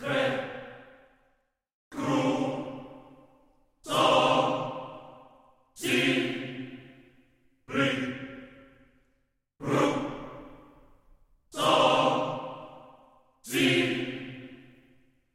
电影合唱团2
Tag: 125 bpm Cinematic Loops Choir Loops 2.58 MB wav Key : Unknown